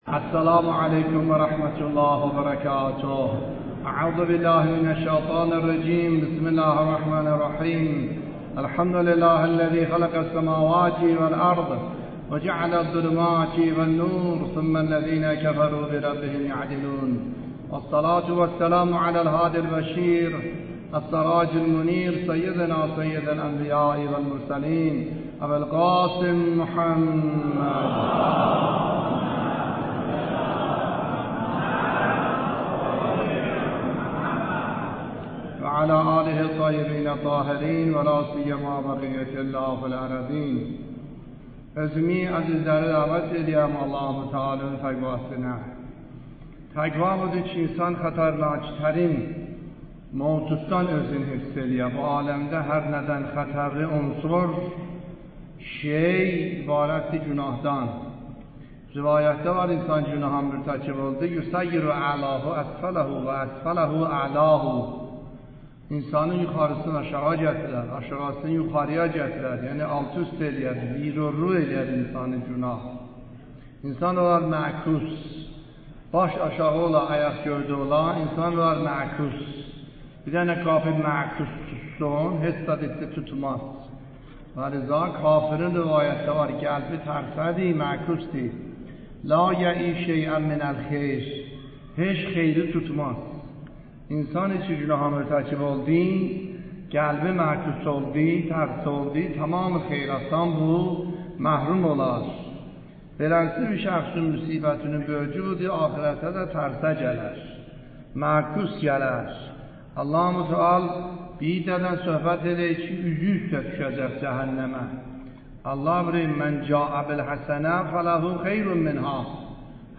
خطبه های نماز جمعه مورخ ۹۵/۲/۲۴